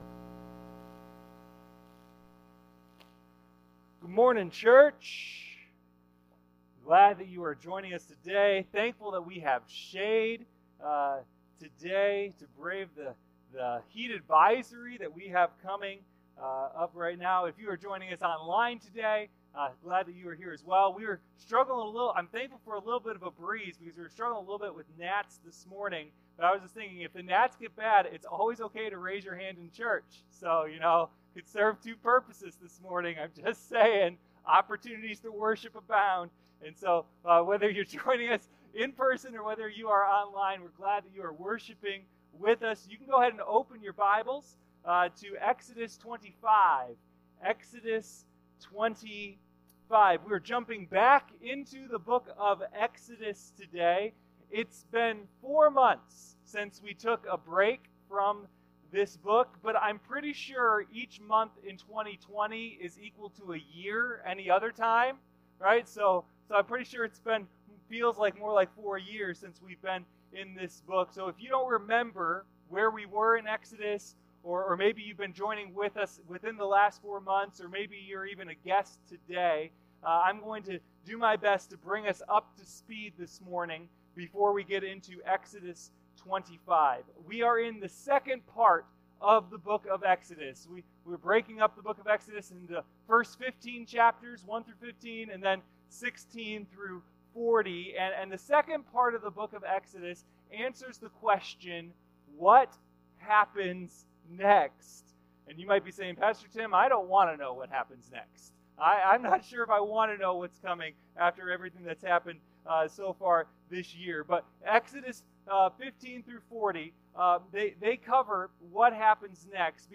Sunday Morning Communion: A Study in Exodus Exodus Watch Listen Save Exodus 25:1-40 The Lord said to Moses